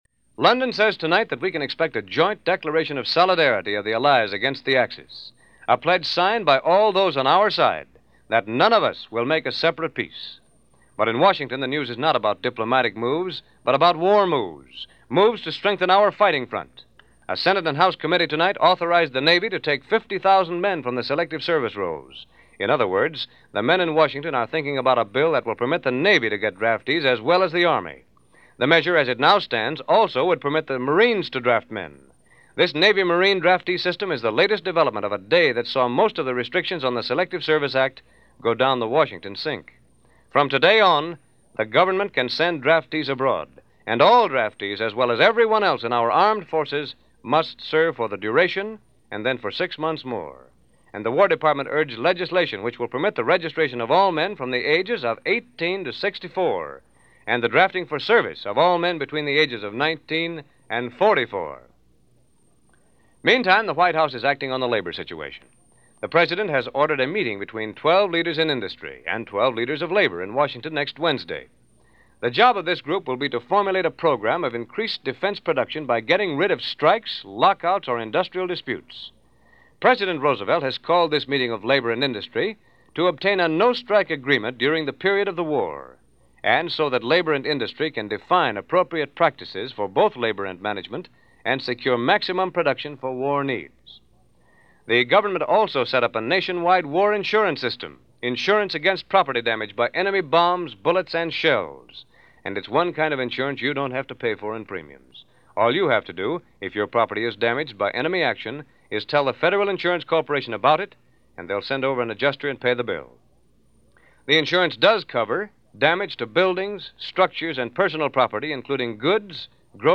December 13, 1941 – News Of The World – NBC Radio